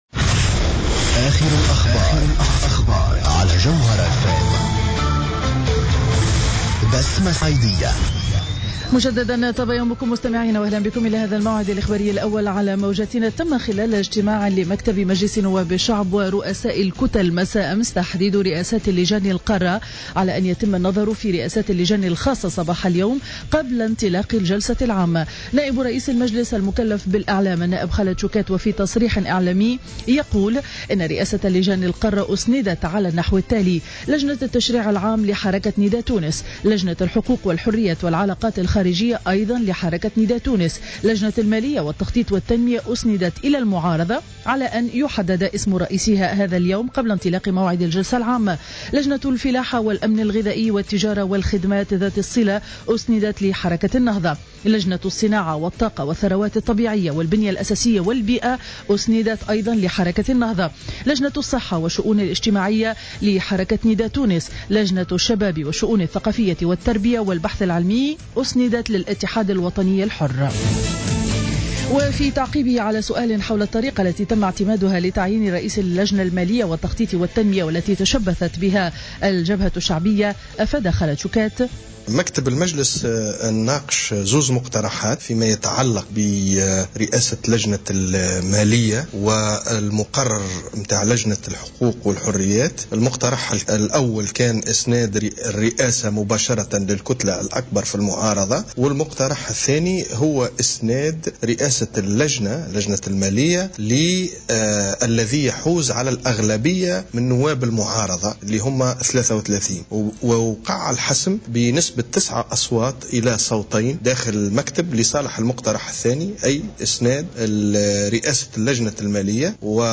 نشرة أخبار السابعة مساء ليوم الجمعة 20 فيفري 2015